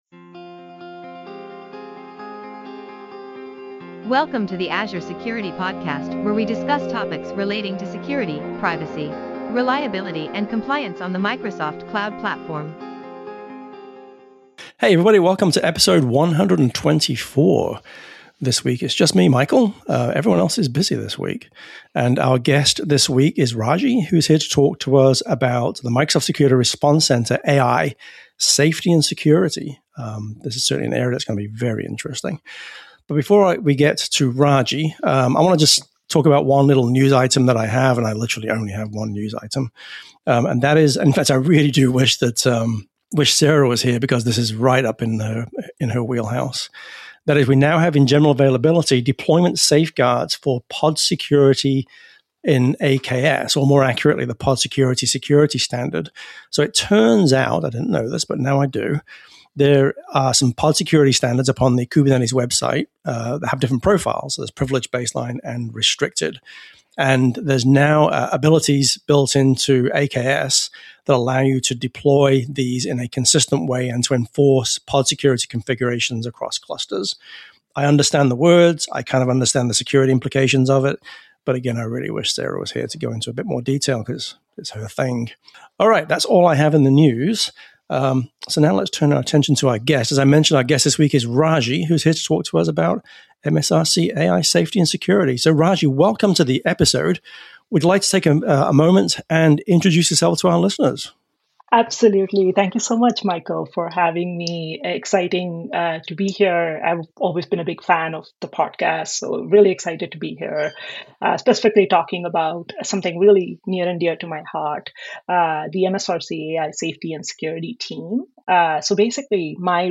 This week on the GeekWire Podcast: We hit the road for a driving tour of the week’s news, making stops at Starbucks, Microsoft, and an Amazon Fresh store in its final days.